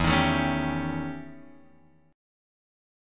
E Minor Major 13th